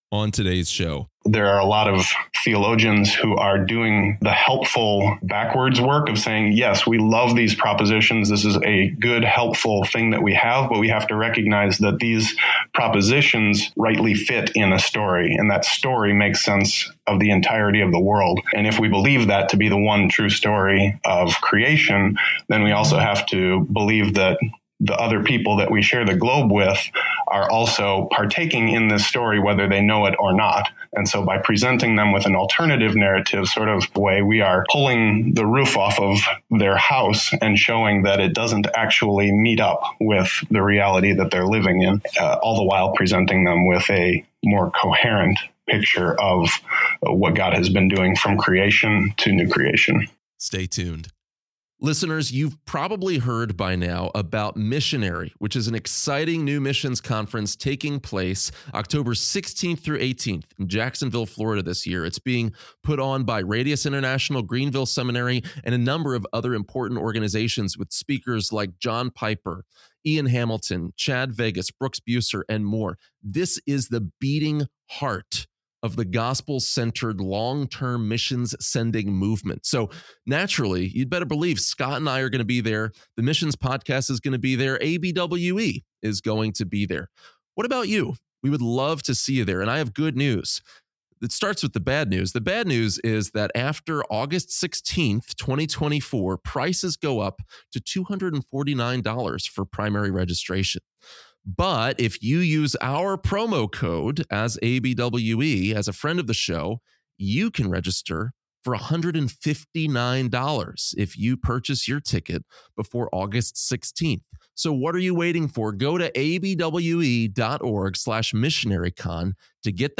Why is it so difficult to share the gospel with Muslims? This week we dig into The Missions Podcast archives for a conversation